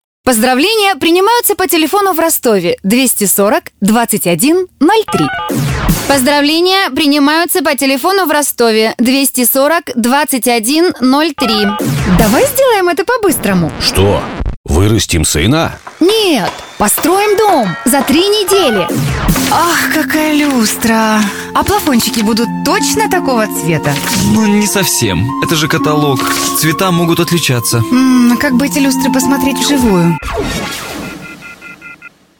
Professionell female voice over artist from Russia.
russische Sprecherin
Sprechprobe: Werbung (Muttersprache):
female russian voice over talent